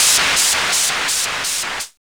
1707R SYN-FX.wav